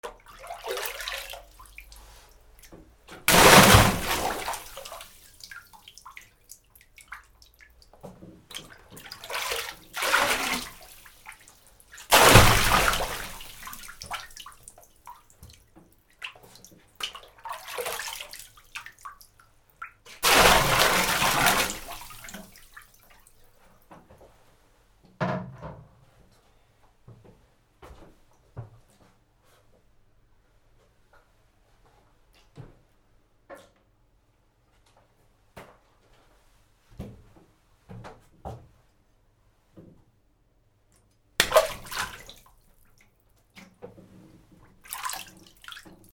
水に落ちる 洗面器から水を勢いよく落とす
『ザザン』